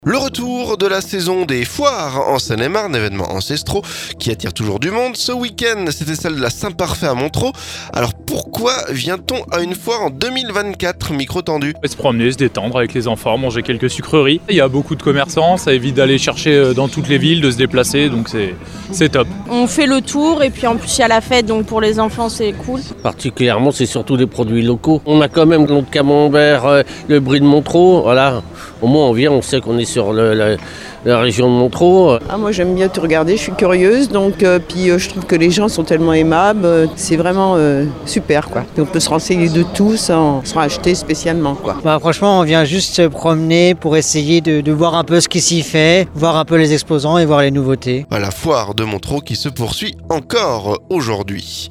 MONTEREAU - Gourmandises, animations, artisans... Les visiteurs de la foire s'expriment...
La foire de Montereau encore aujourd'hui.